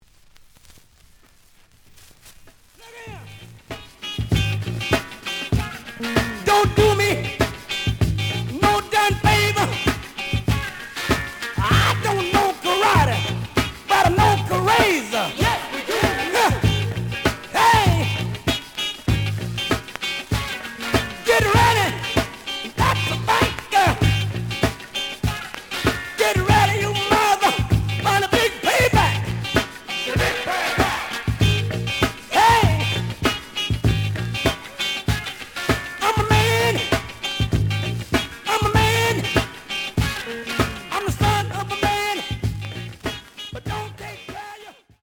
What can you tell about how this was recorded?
The audio sample is recorded from the actual item. Slight noise on both sides.